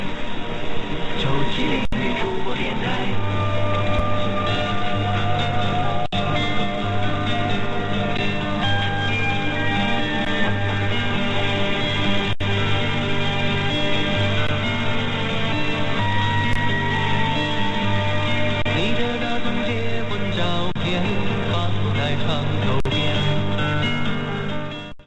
Tilalle tuli tämä 97.0 Hostess Radio eli kiinaksi 9-7-0 (jiu-qi-ling) Nǚ zhǔbō, joka nimensä mukaisesti käyttää vain naisjuontajia.